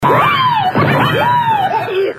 PLAY The Noid TV Laugh
noid-laugh.mp3